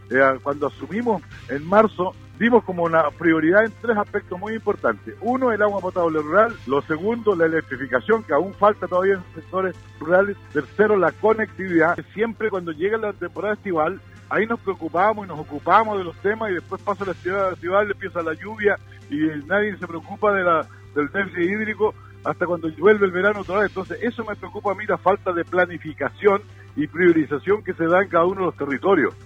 En conversación con Radio Sago, el presidente del Consejo Regional de Los Lagos, Juan Cárcamo, se refirió a los pocos proyectos de Agua Potable Rural que han sido presentados al Core.